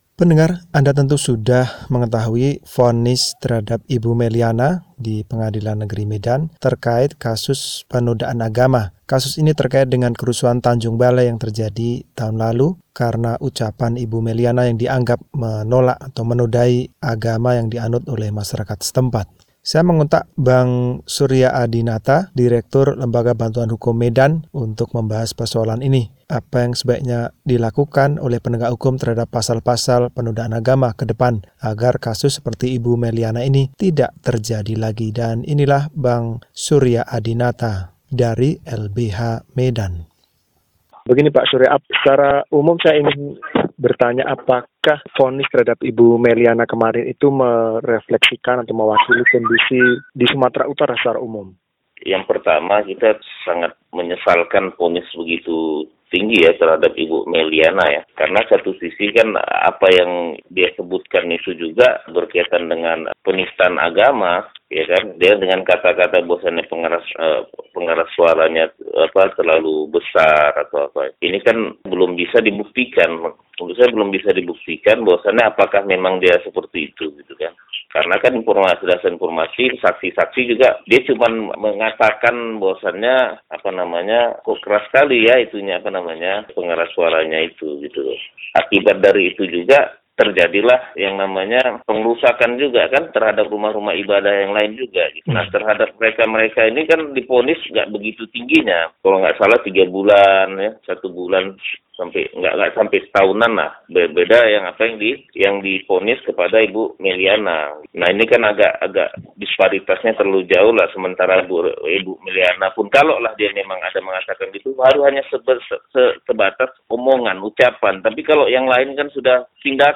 Perbincangan dengan Direktur Lembaga Bantuan Hukum